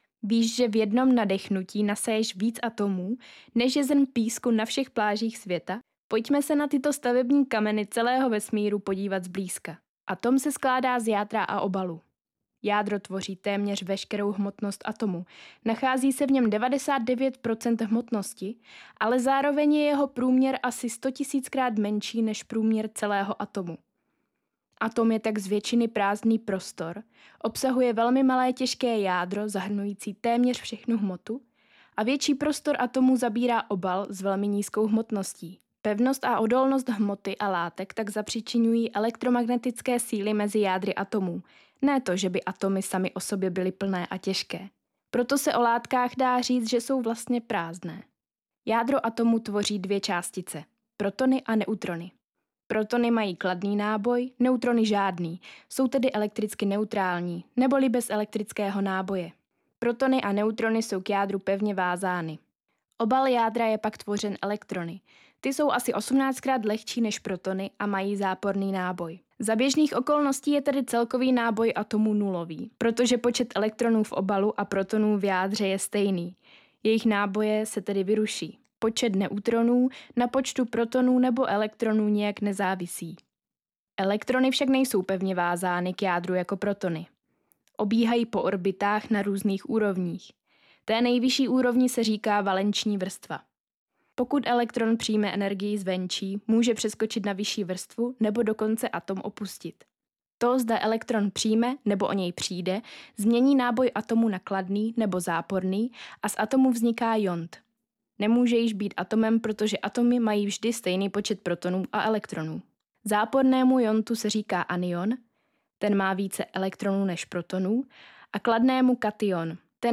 Umím: PR články, Voiceover, Korektura textů
Profesionální ženský hlas - voiceover/dabing (do 400 znaků)